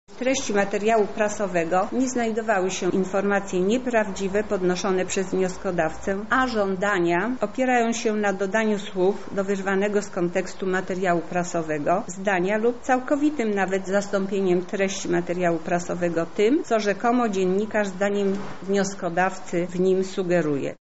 tłumaczy sędzia Lilianna Stępkowska.